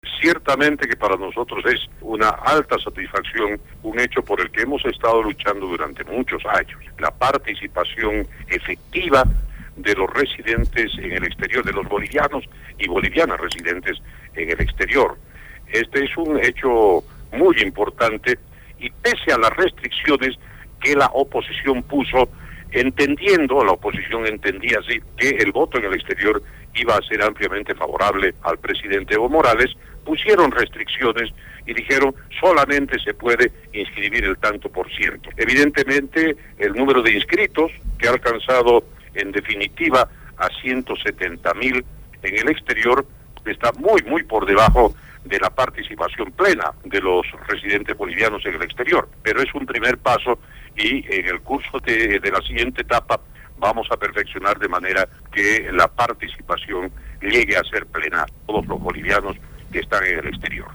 Antonio Peredo, Senador del MAS (Movimiento al Socialismo) por La Paz